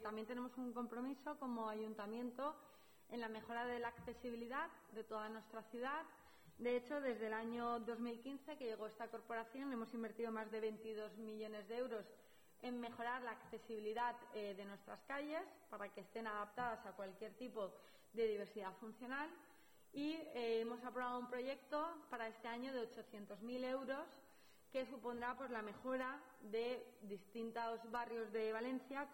Sandra Gómez. Rueda de prensa posterior a la junta de Gobierno